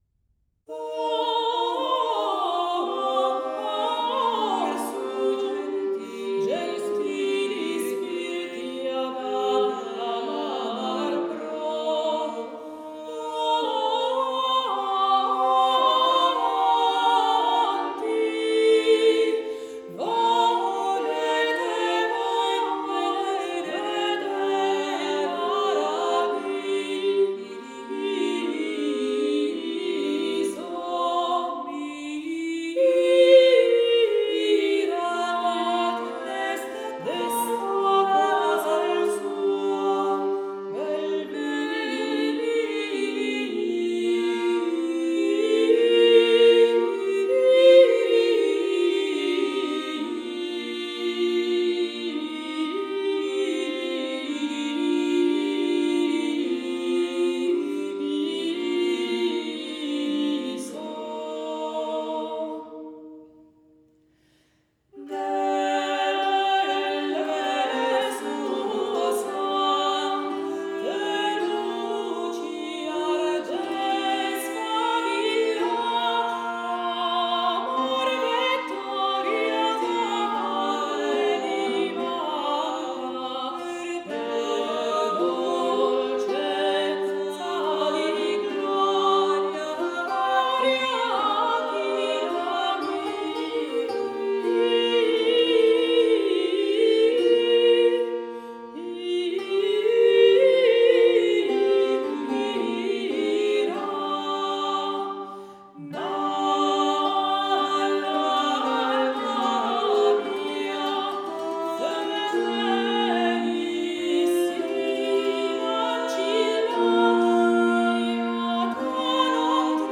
Le parole della ballata son queste:   Musica.